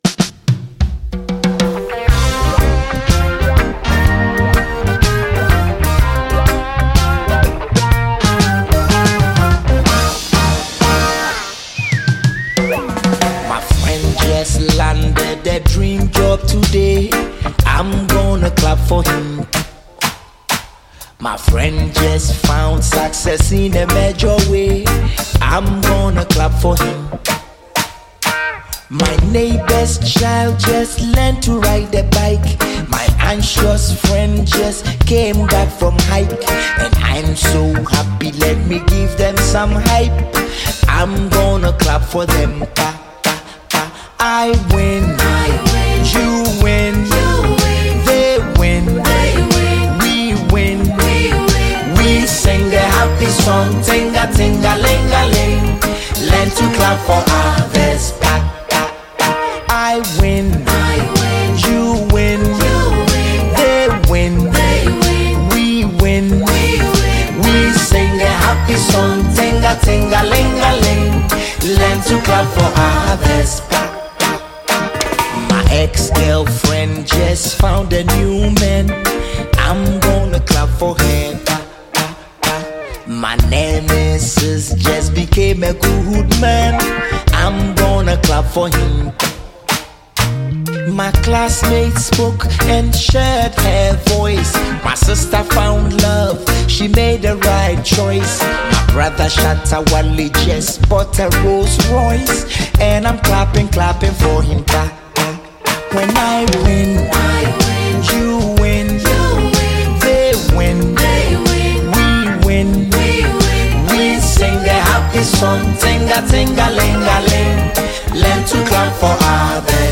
Ghana’s rap doctor
heartfelt and uplifting single